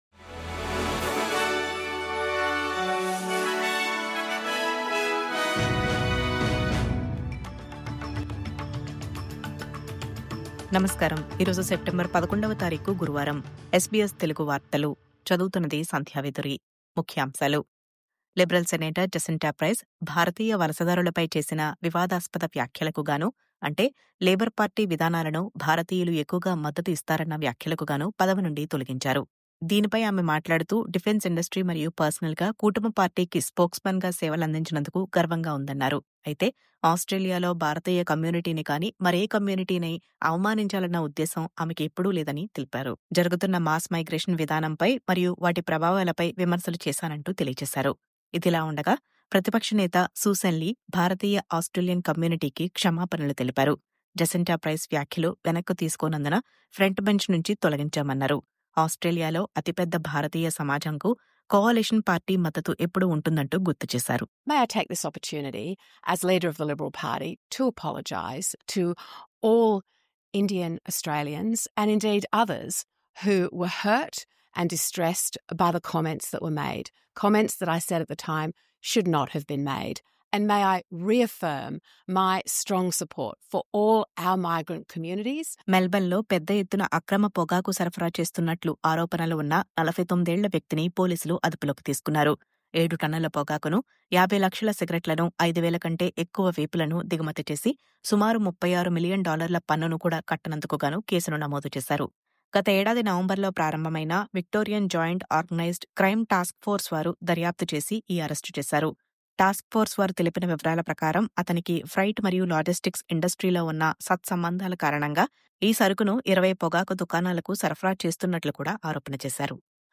News update: భారతీయ వలసదారులపై వివాదాస్పద వ్యాఖ్యలు… క్షమాపణ నిరాకరించిన జసింతా ప్రైస్‌.. పదవి తొలగింపు..